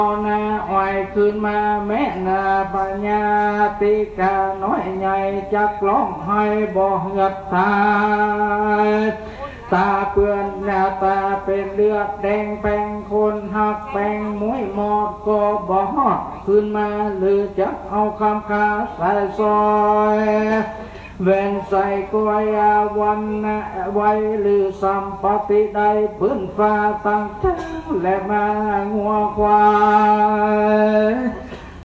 Buddhist Funeral in Thailand
Chanting and the Procession
A chair was quickly provided for me to sit and listen to the rhythmic chanting, which seemed to be endless.
Buddhist monk chanting into the microphone.
Buddhist-Funeral-Prayers-1.m4a